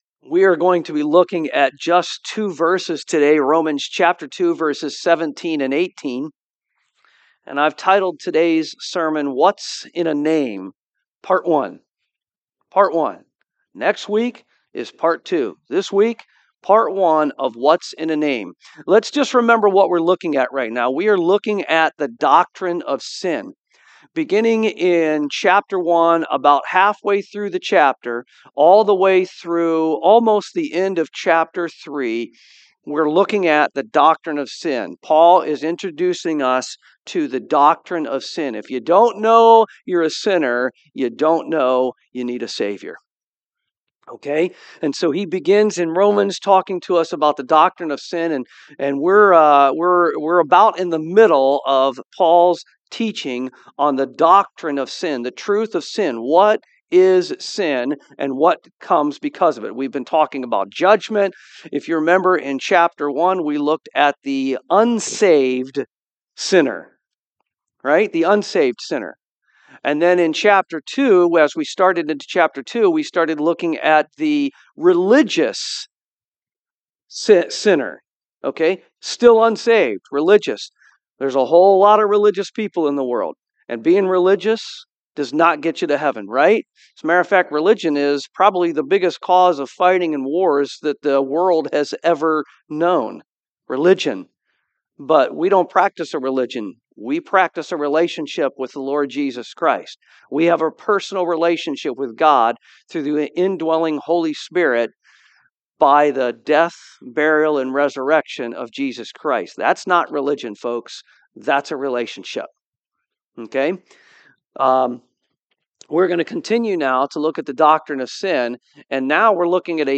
Romans 2:17-18 Service Type: AM Our faith must be more than a name we call ourselves